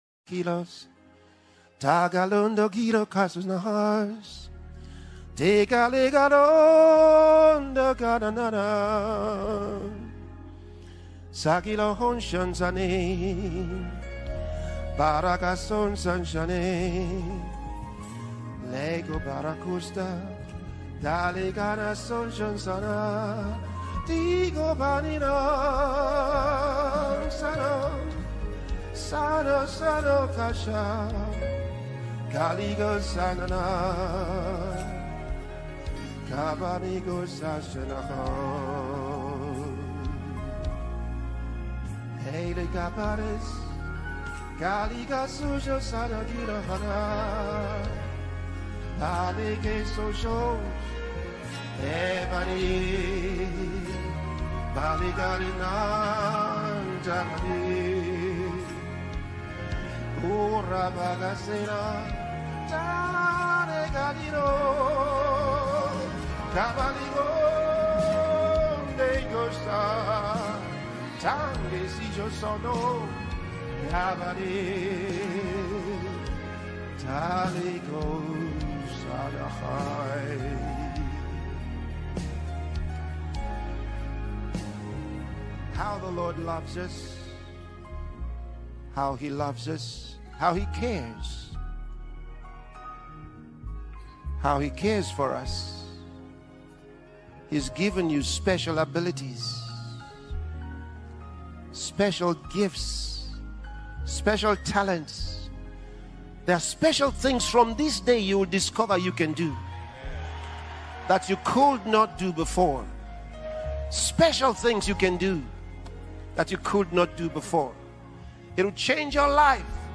Pastor Chris sings in tongues sound effects free download
live healing service march 2024